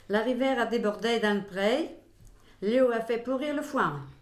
Langue Maraîchin
Collecte de locutions vernaculaires
Catégorie Locution